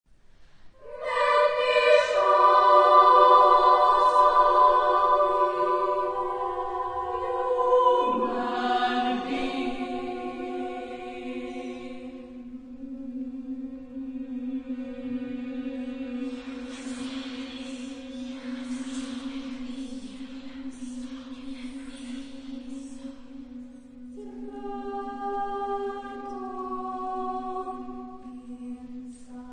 Genre-Stil-Form: Motette ; weltlich
Chorgattung: SSSSSAAAA  (9 Frauenchor Stimmen )
Tonart(en): frei